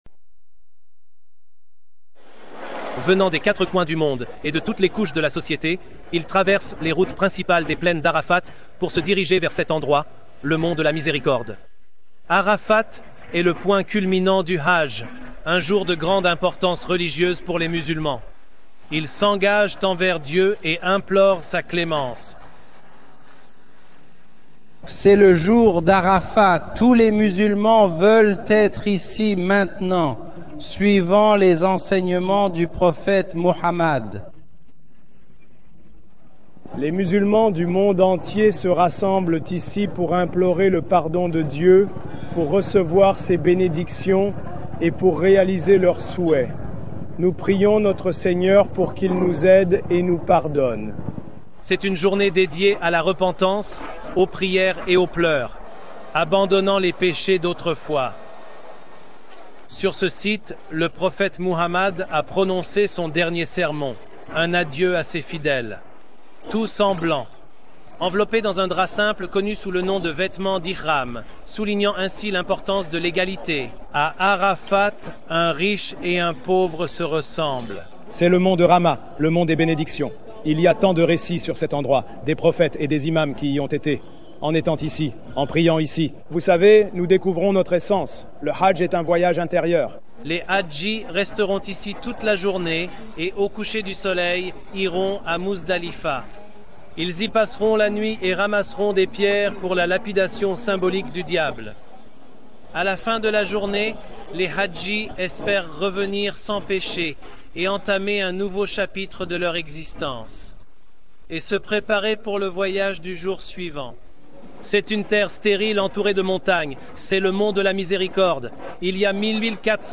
Description: Cette vidéo est un reportage d'Al-Jazeera TV sur les vertus du Hajj.